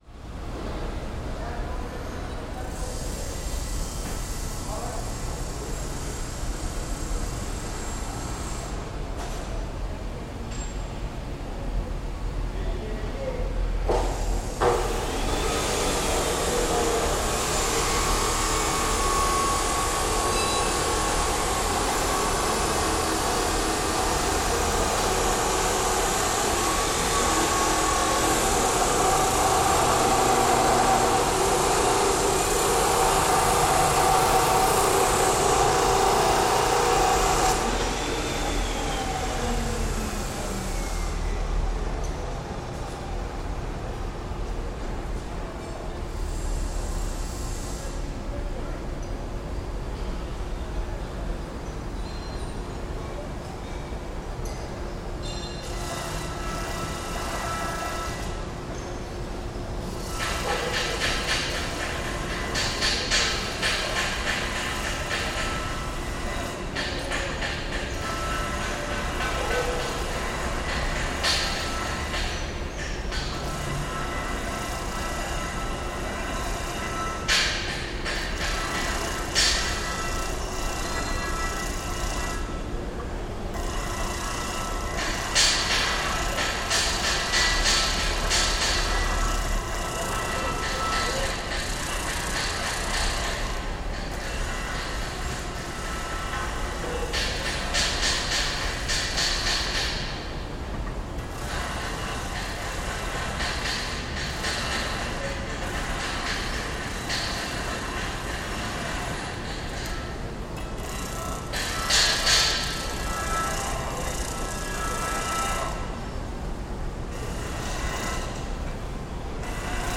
Шум производственного складского помещения № 2